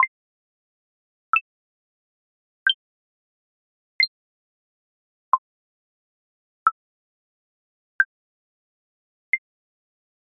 Звуки всплывающего окна
Еще серия деревянные